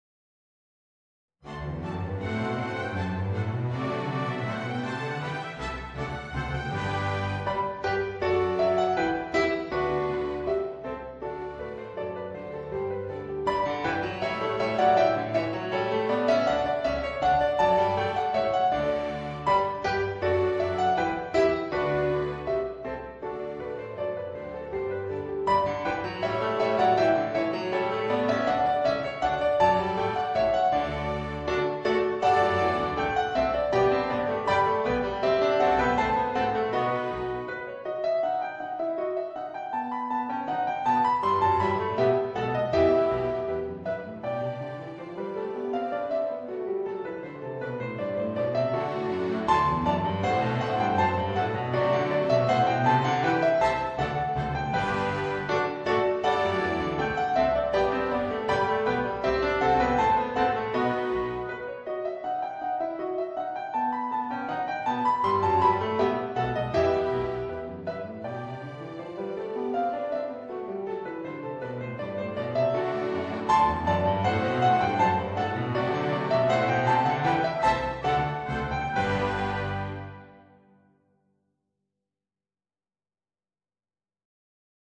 Voicing: Piano and Orchestra